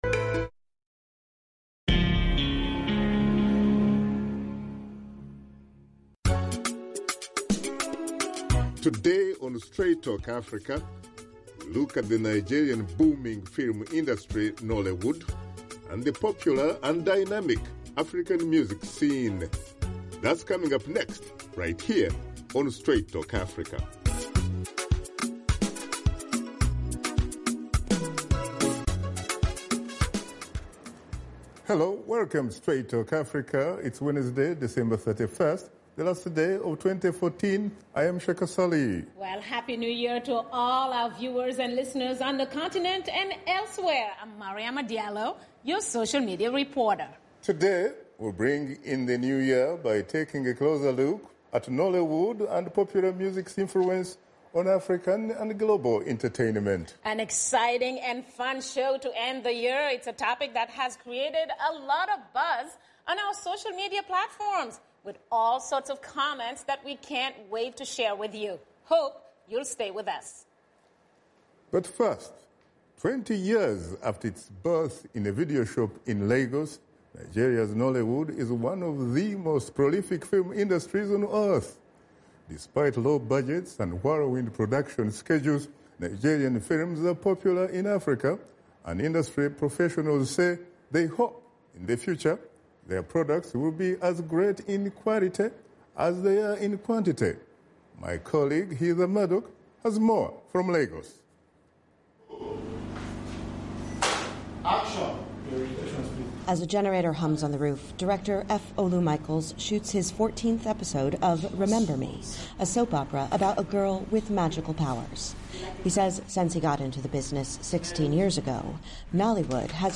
Host Shaka Ssali and his guests discuss Nollywood’s influence on African and global entertainment. Washington Studio Guests